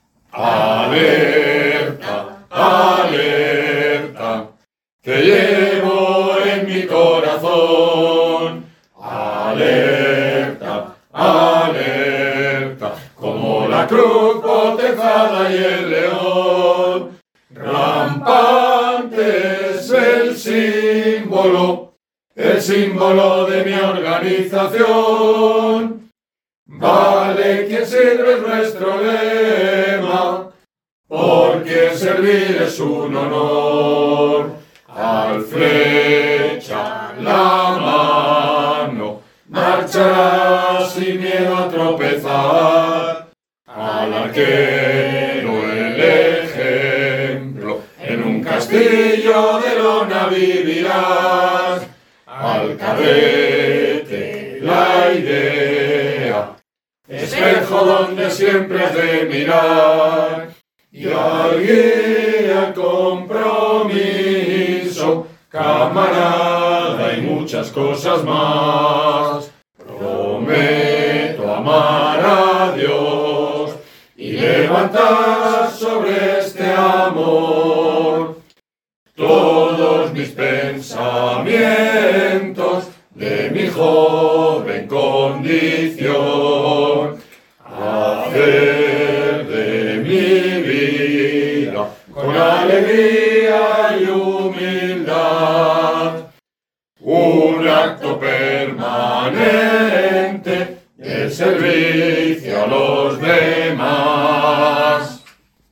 Recogemos esta canción de un cancionero de OJE de los años 80, con un mix de canciones que posiblemente haya sido editado para un campamento.